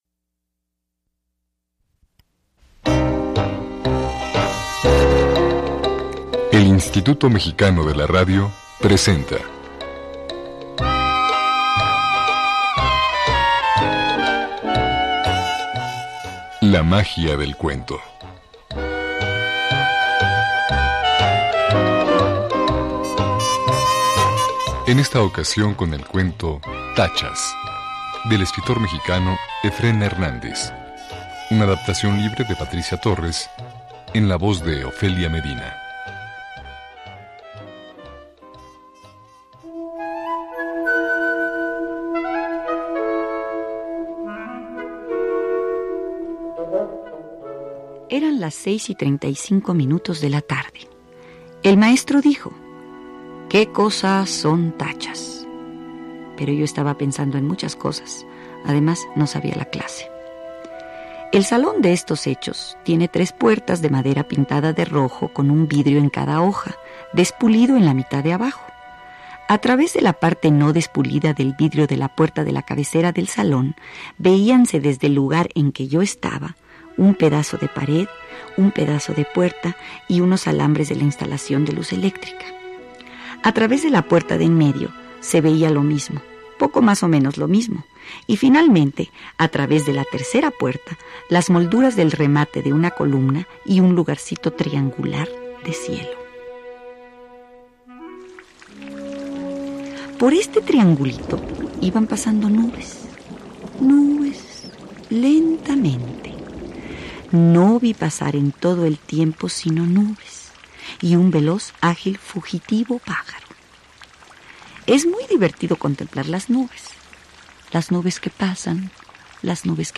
Escucha “Tachas” en la voz de Ofelia Medina, en el programa “La magia del cuento”, transmitido en 1993.